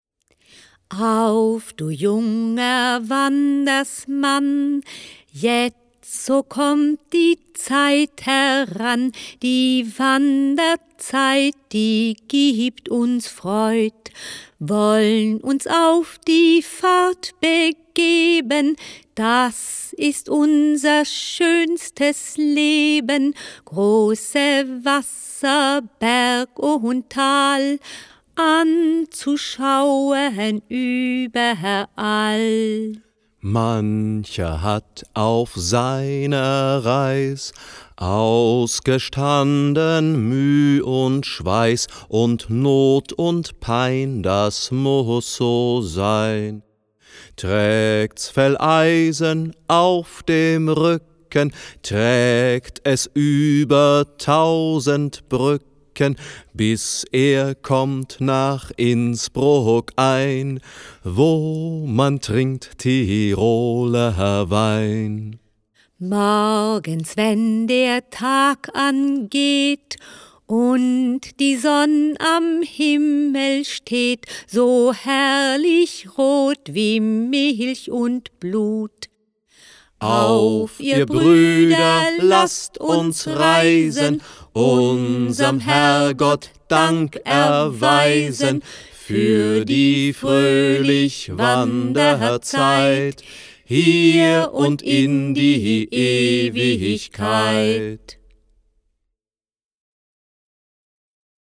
Schlagworte Aktivierung • Altenarbeit • Alzheimer • Alzheimer Beschäftigung • Alzheimer Geschenk • Alzheimer Geschenkbuch • Alzheimer Musik • Alzheimer Spiele • Bilderbuch • Biographiearbeit • Bücher für alzheimerkranke Menschen • Bücher für Alzheimer Patienten • Bücher für demente Menschen • Bücher für demenzkranke Menschen • Bücher für Demenz Patienten • Dementenarbeit • Demenz • Demenz Beschäftigung • Demenz Geschenk • Demenz Geschenkbuch • Demenz Musik • Demenz Spiele • Geschenk • Geschenkbuch • Hörbuch • Liederbuch • Musiktherapie • Soundbuch • Soundchip • Tonleistenbuch • Volkslieder • Wanderlieder